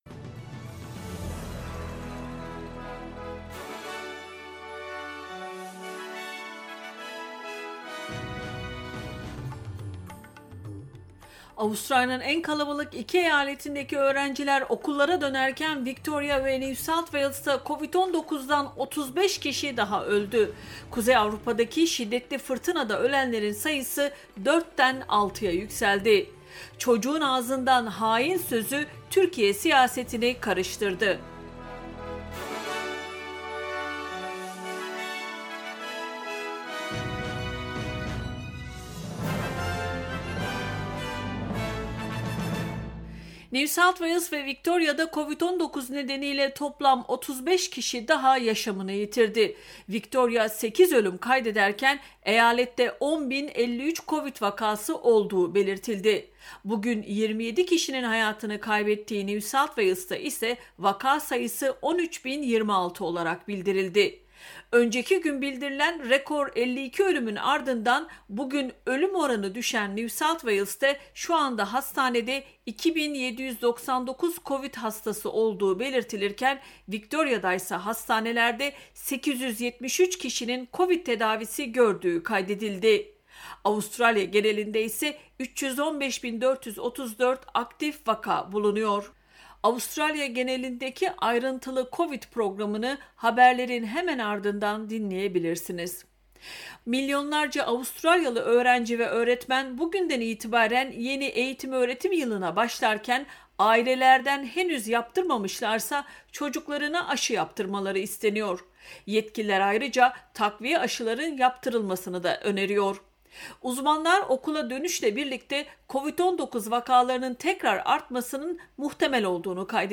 SBS Türkçe Haberler 31 Ocak